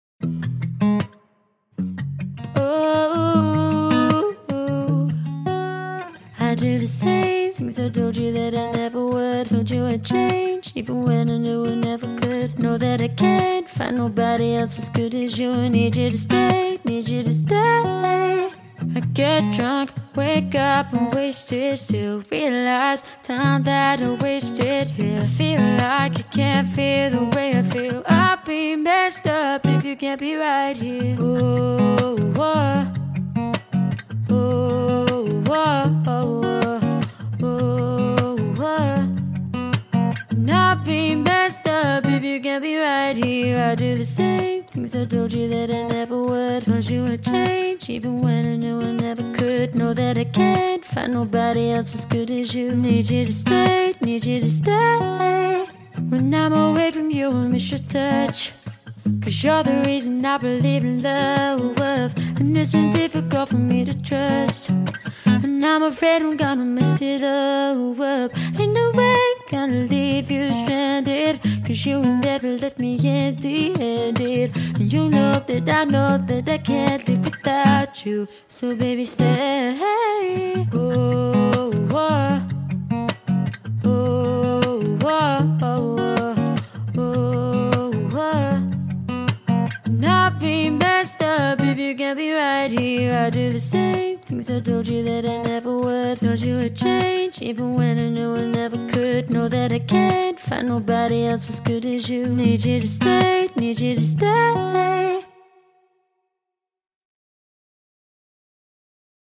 Female Solo Acoustic Guitarist for Hire